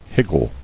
[hig·gle]